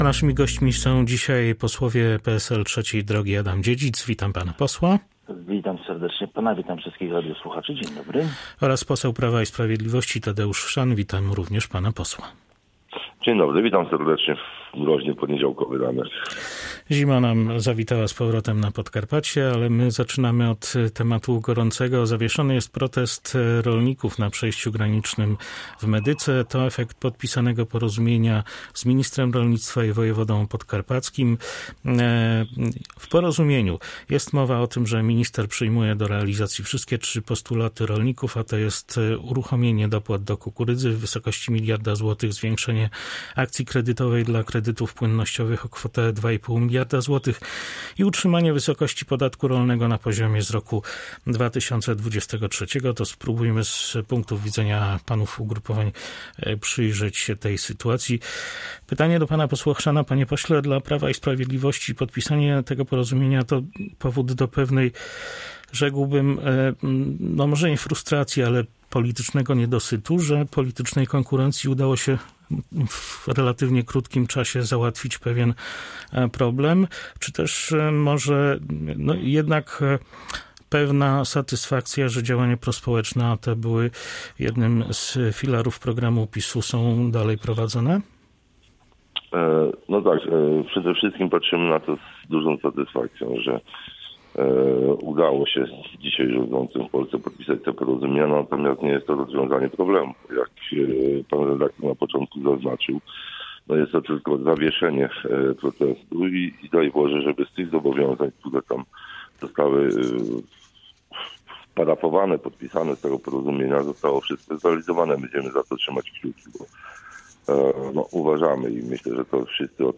– powiedział na antenie Polskiego Radia Rzeszów poseł PiS Tadeusz Chrzan.